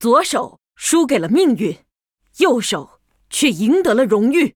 文件 文件历史 文件用途 全域文件用途 Lobato_amb_02.ogg （Ogg Vorbis声音文件，长度4.5秒，98 kbps，文件大小：54 KB） 文件说明 源地址:游戏语音 文件历史 点击某个日期/时间查看对应时刻的文件。 日期/时间 缩略图 大小 用户 备注 当前 2018年11月17日 (六) 03:33 4.5秒 （54 KB） 地下城与勇士  （ 留言 | 贡献 ） 分类:洛巴赫 分类:地下城与勇士 源地址:游戏语音 您不可以覆盖此文件。